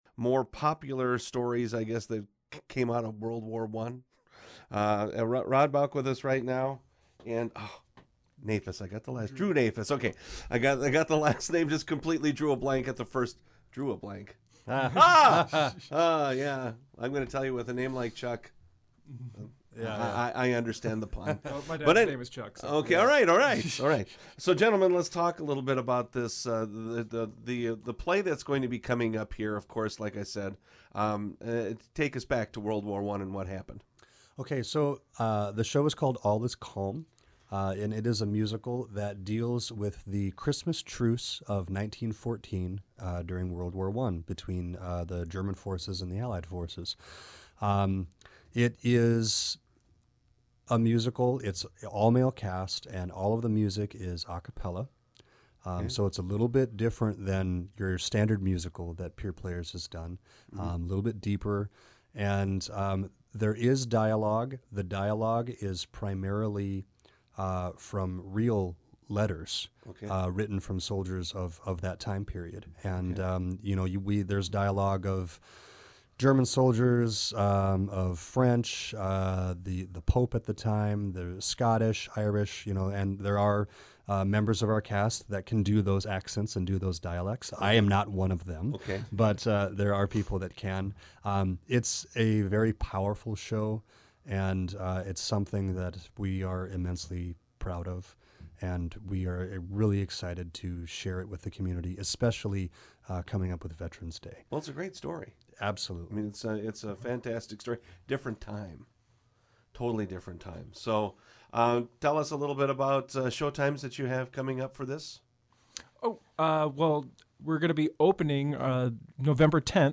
Members of the cast of Pierre Players current production, “All is Calm,” previewed the show.